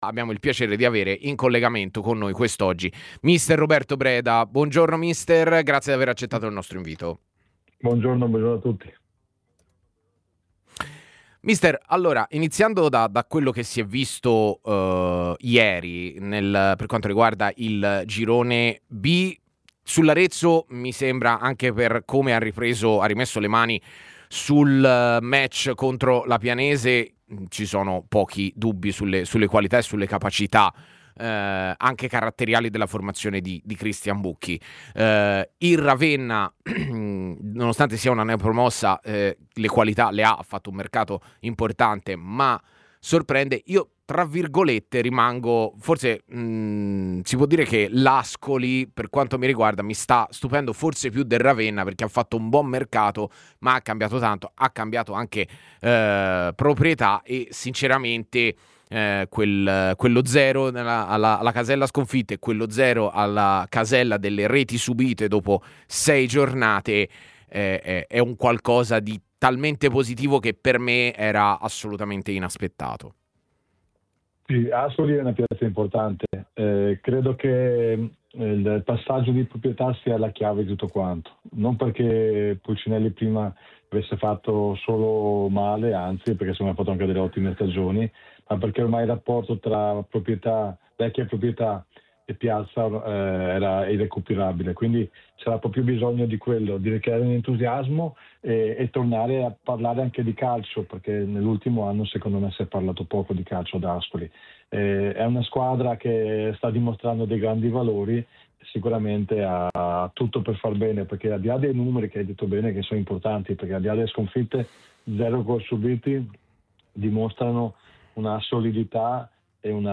Roberto Breda , tecnico con un passato su panchine importanti come quelle di Livorno, Perugia, Ternana, Ascoli e Salernitana, ha analizzato i risultati della sesta giornata del campionato di Serie C per quanto riguarda il Girone B e non solo attraverso i microfoni di TMW Radio all'interno della trasmissione 'A Tutta C':